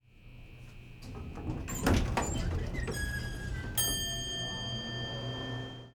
Office_elevator_door open_ding
ding door-open elevator office sound effect free sound royalty free Sound Effects